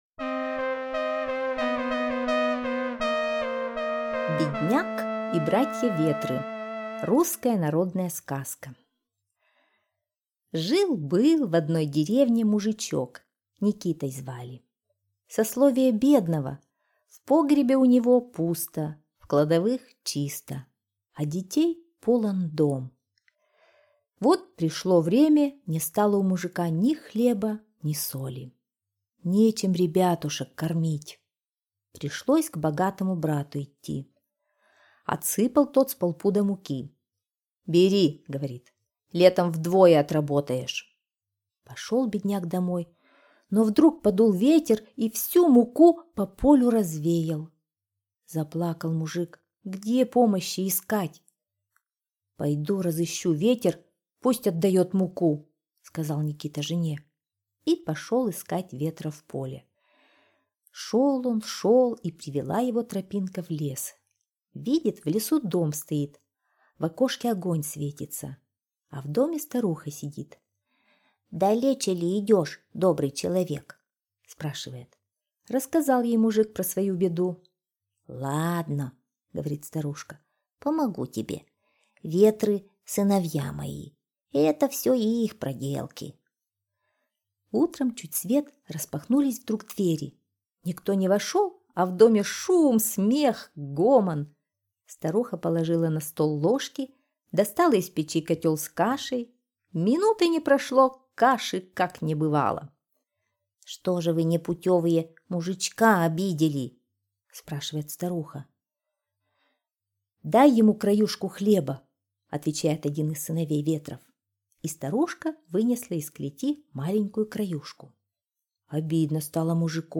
Бедняк и братья-ветры — русская народная аудиосказка.